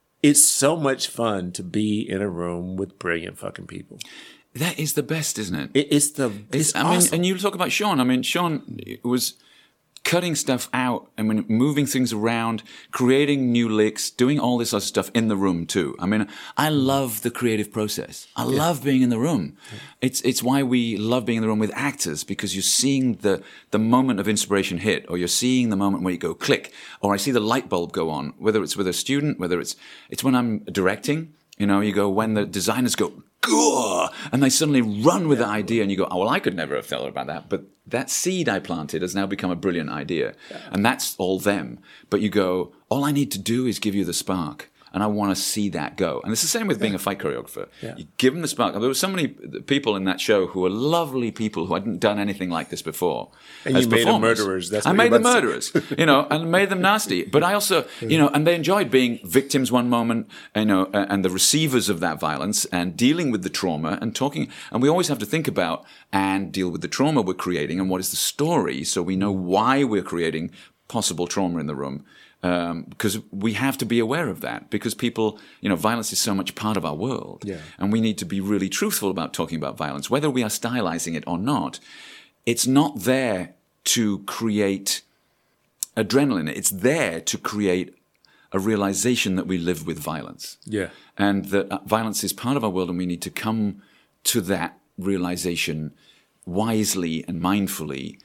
Choreographers and composers gathered here, with our talented cast to discuss the song, the movement, and the historical moment that closes this riveting new musical.